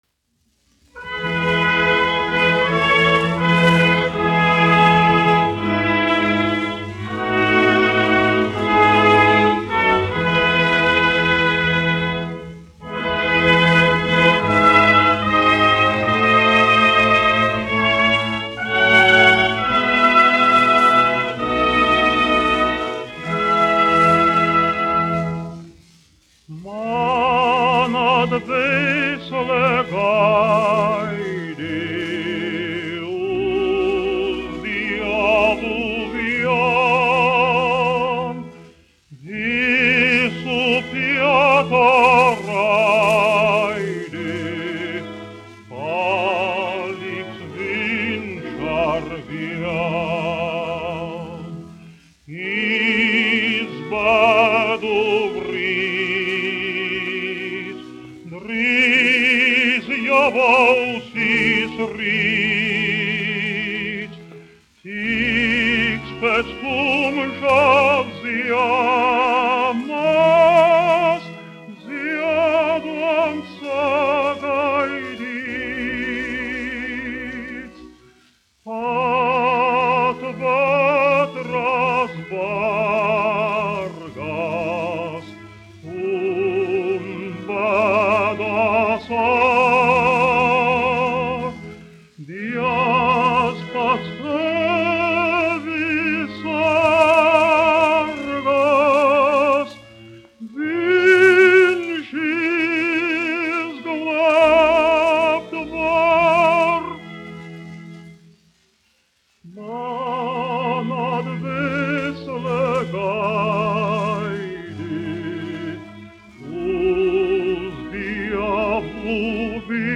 A. Pļiševskis, aranžētājs
Mariss Vētra, 1901-1965, dziedātājs
1 skpl. : analogs, 78 apgr/min, mono ; 25 cm
Garīgās dziesmas
Latvijas vēsturiskie šellaka skaņuplašu ieraksti (Kolekcija)